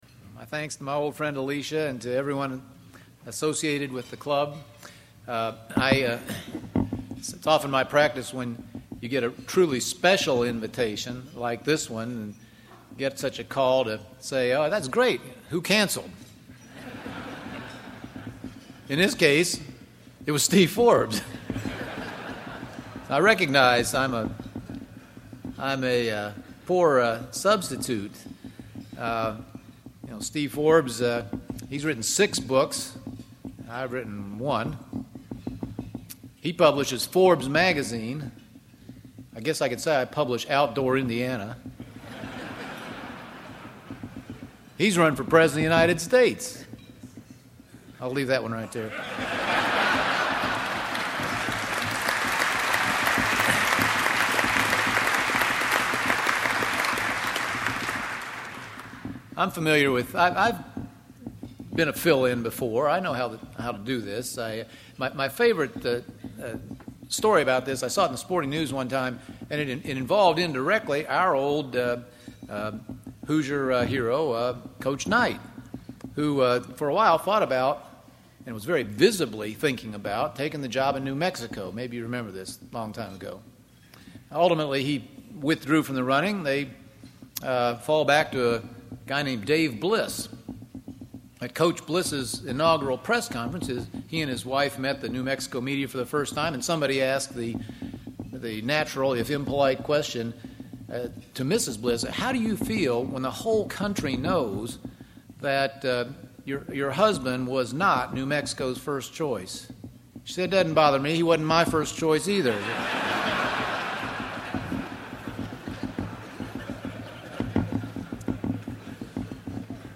March 24, 2010- Governor Mitch Daniels provided the keynote address at the Economic Club of Indiana's monthly luncheon. The speech is entitled: Indiana's Economy and Its Prospects.